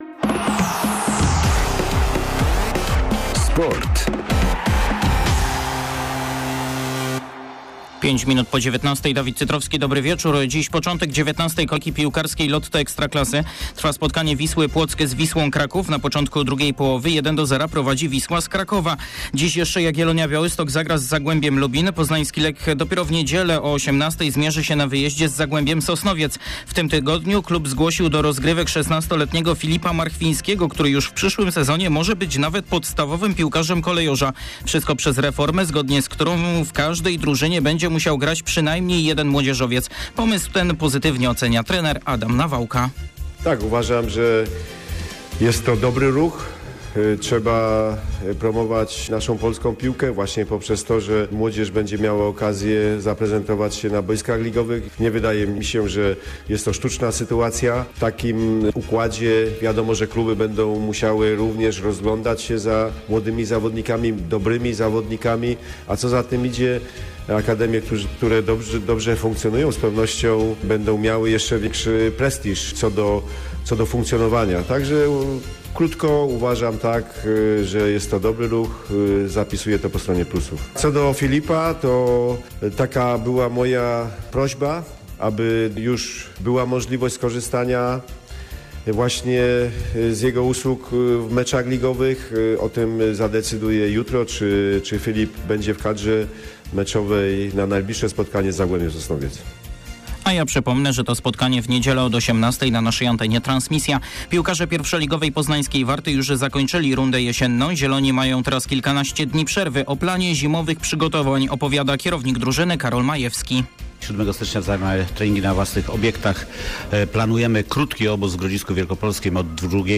14.12. serwis sportowy godz. 19:05